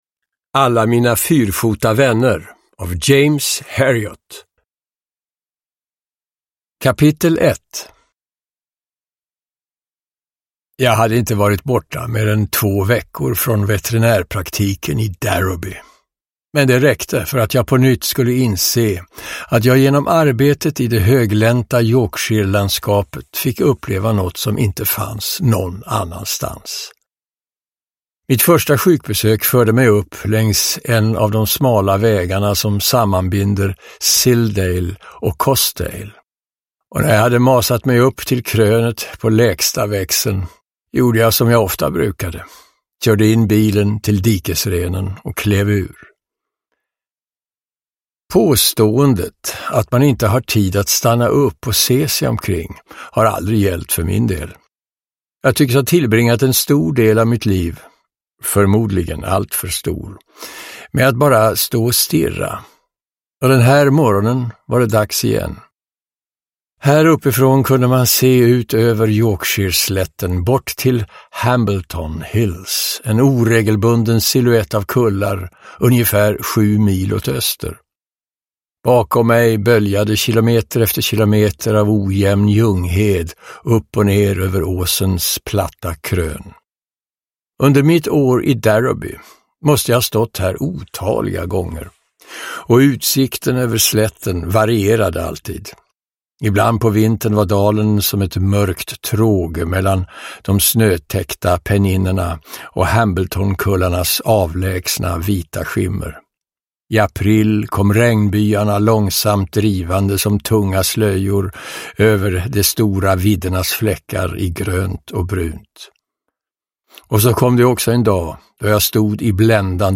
Alla mina fyrfota vänner – Ljudbok – Laddas ner
Uppläsare: Björn Granath